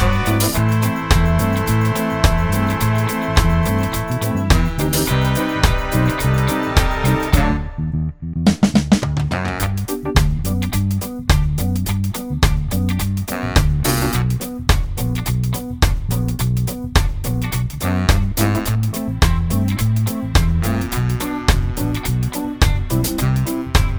no Backing Vocals Reggae 3:20 Buy £1.50